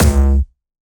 Jumpstyle Kick 2
9 C#2.wav